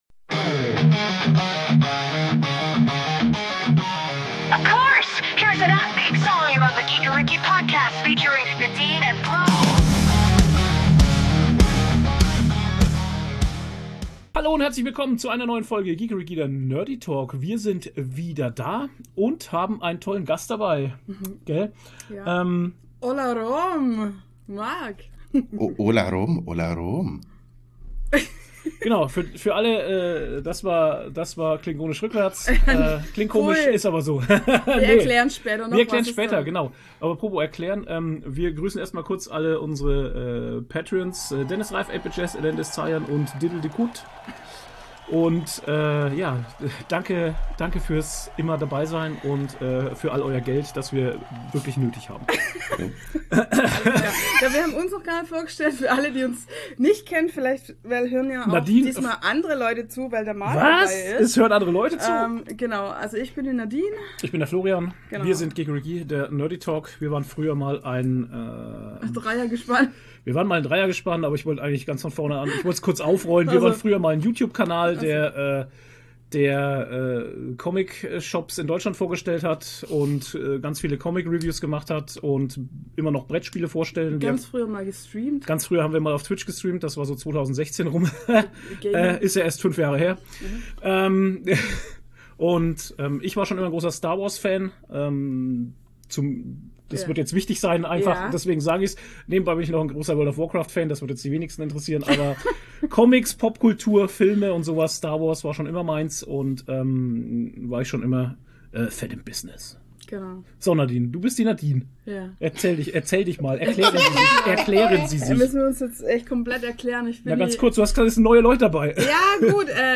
Wir haben uns mal wieder einen Gast eingeladen und quatschen über unsere Erlebnisse auf der Comic Con Dornbirn, wie es sich anfühlt zum ersten mal seinen Podcast live auf der Bühne aufzunehmen, über Mandalorianer, Serien die wir abgebrochen haben, die Anf...